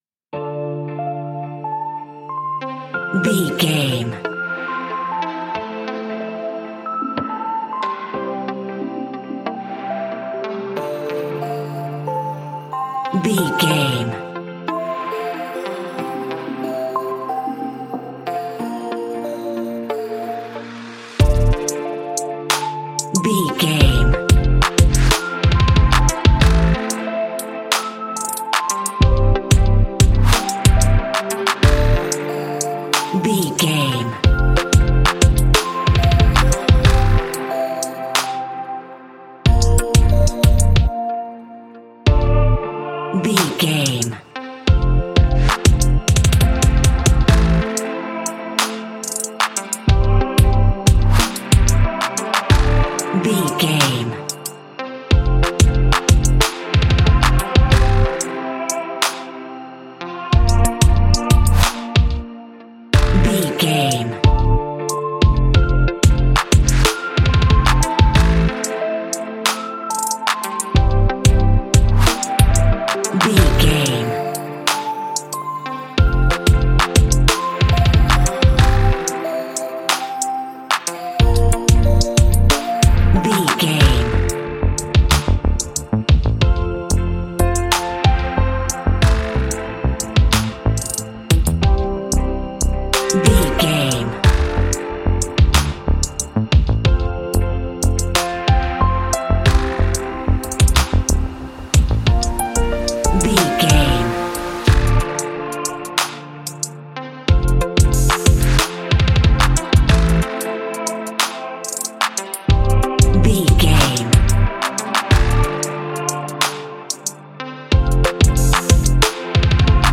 Ionian/Major
electric piano
brass
synthesiser